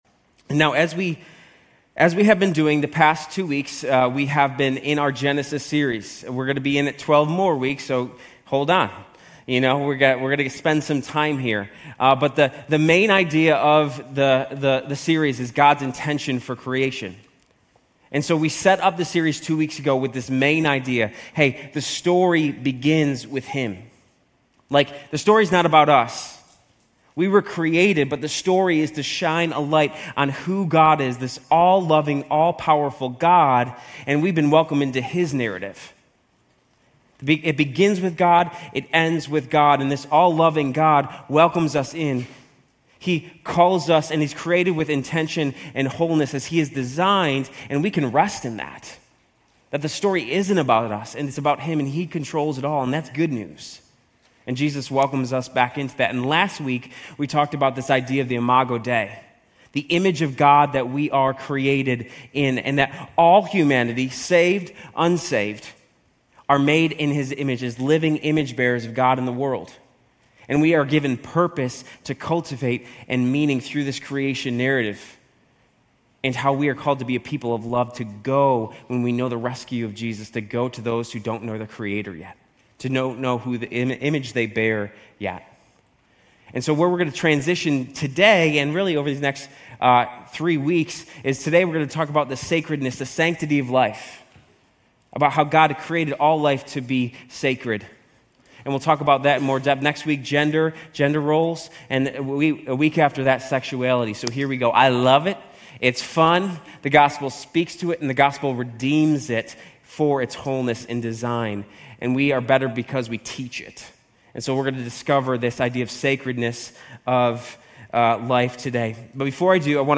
Grace Community Church University Blvd Campus Sermons Genesis 1:26-27 - Sanctity of Life Sep 02 2024 | 00:35:29 1x 00:00 / 00:35:29 Subscribe Share RSS Feed Share Link Embed